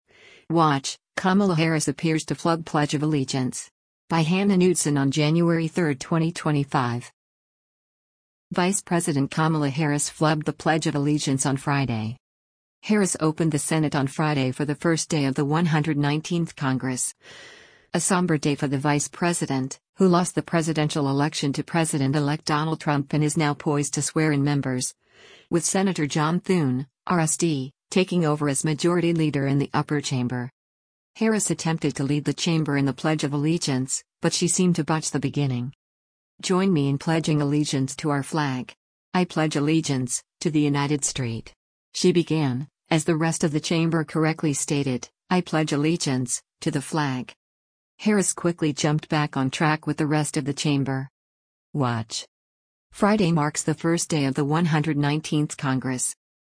Harris attempted to lead the chamber in the Pledge of Allegiance, but she seemed to botch the beginning.
“Join me in pledging allegiance to our flag. I pledge allegiance, to the United St…” she began, as the rest of the chamber correctly stated, “I pledge allegiance, to the flag.”
Harris quickly jumped back on track with the rest of the chamber.